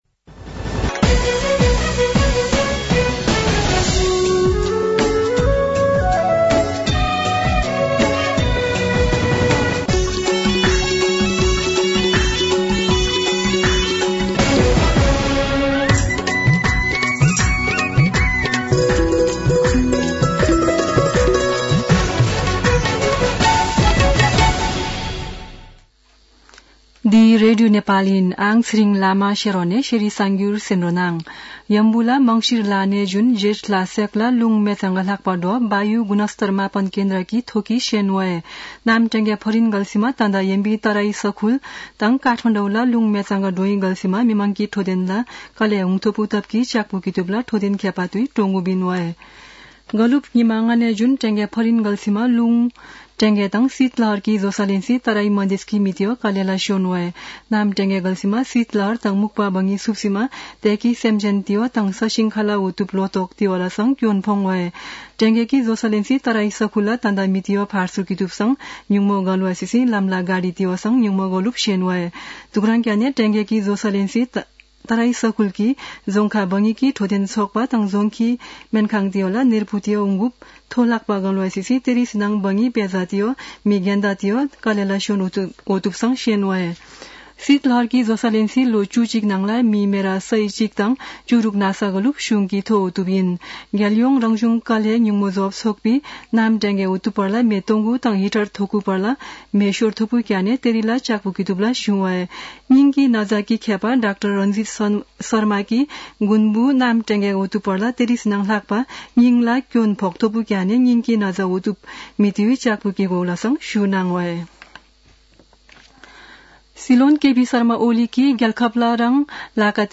शेर्पा भाषाको समाचार : २७ पुष , २०८१
Sherpa-News-3.mp3